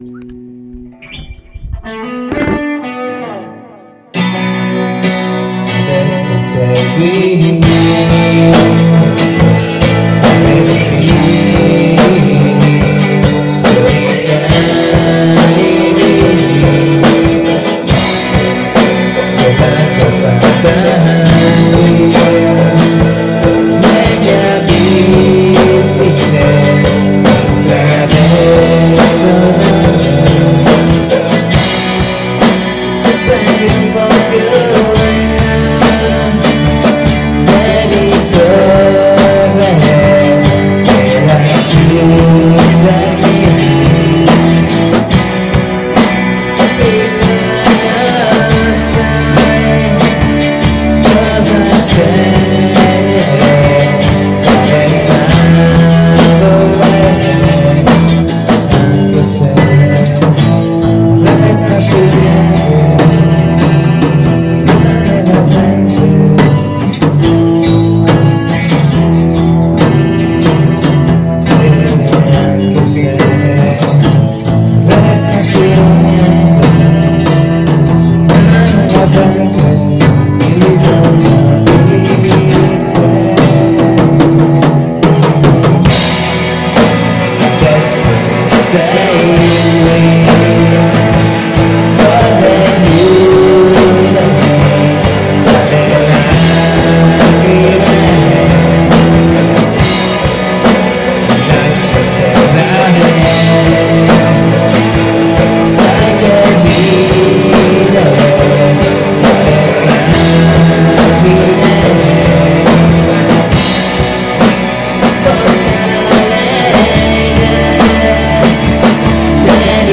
Pop rock italiano